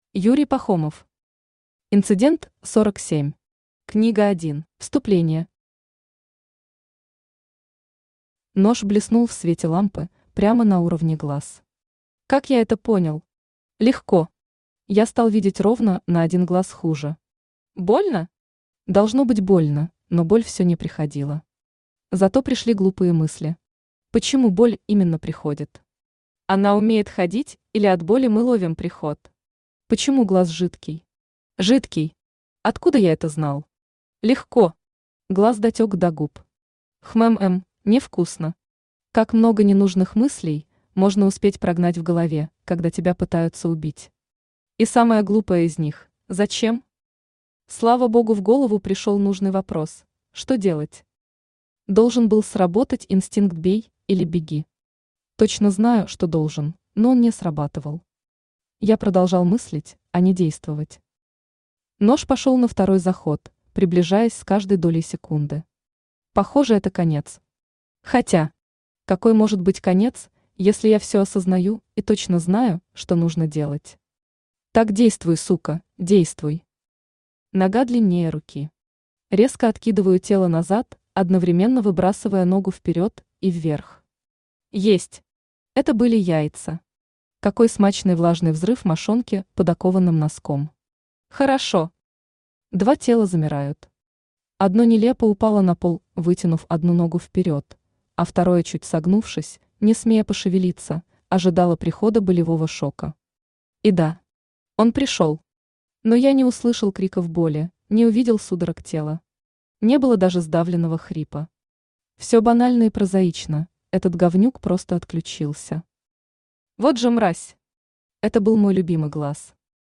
Аудиокнига Инцидент 47. Книга 1 | Библиотека аудиокниг